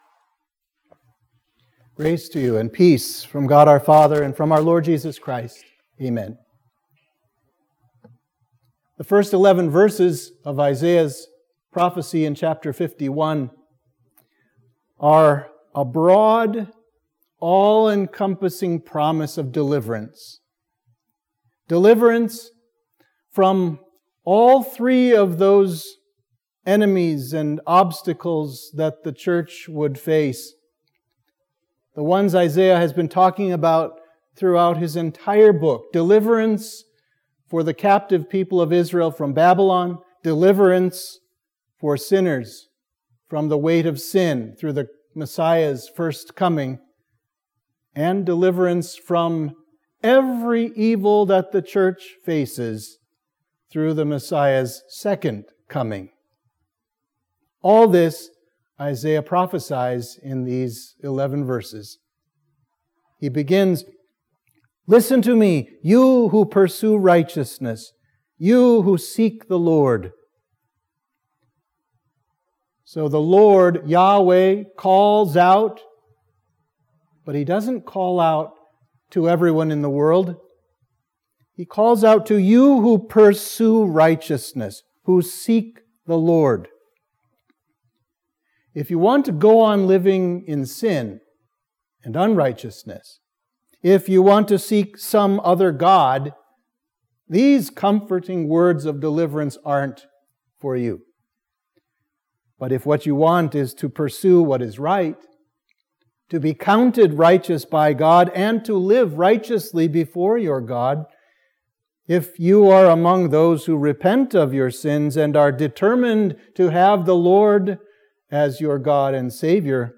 Sermon for Midweek of Trinity 9